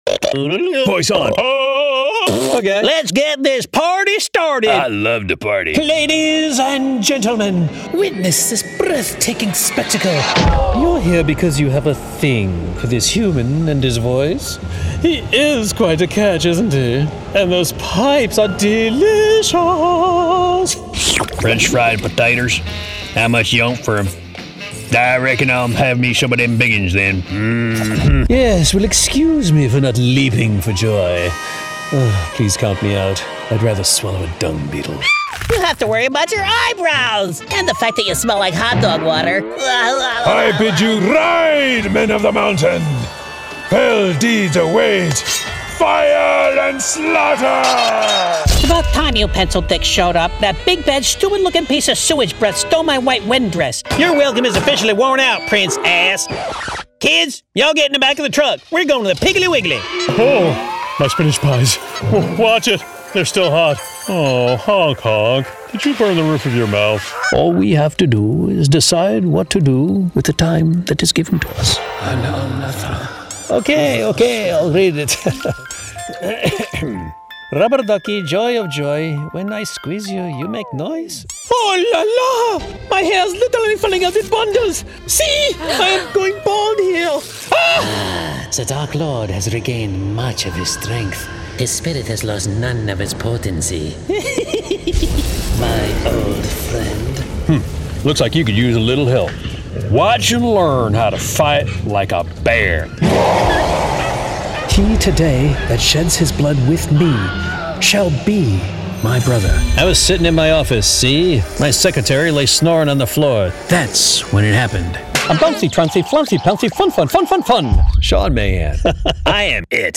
Animation Character Demo